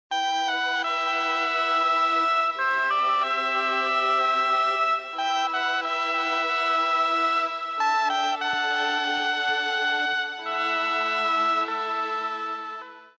Oboe
As plastered liberally all over records by Tangerine Dream such as 'Rubycon', this delightful little sound will add colour to almost anything, and will almost convince people that you're able to play the real thing.
m400oboe.mp3